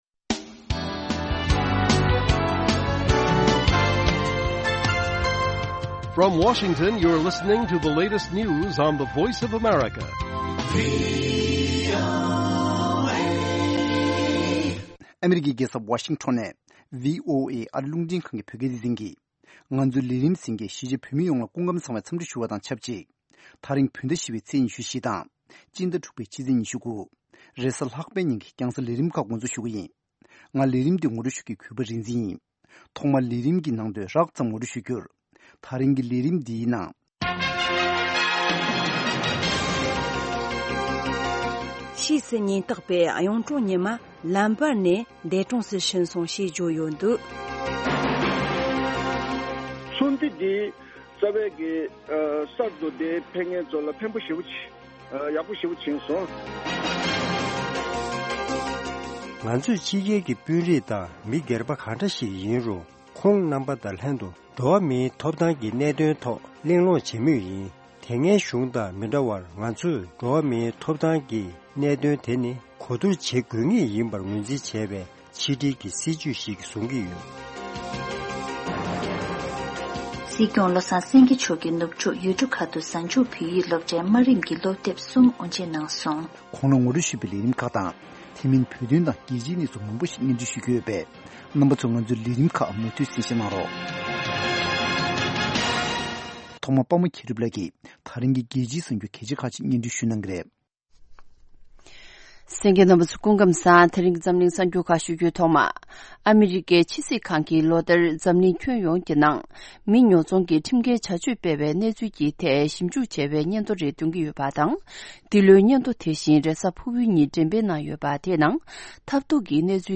Good Morning Tibet Broadcast daily at 08:00 AM Tibet time, the Morning show is a lively mix of regional and world news, correspondent reports, and interviews with various newsmakers and on location informants.